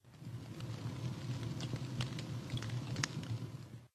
Minecraft Version Minecraft Version 1.21.5 Latest Release | Latest Snapshot 1.21.5 / assets / minecraft / sounds / block / campfire / crackle1.ogg Compare With Compare With Latest Release | Latest Snapshot
crackle1.ogg